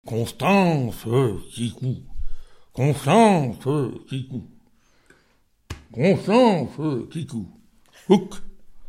Genre brève
Catégorie Pièce musicale inédite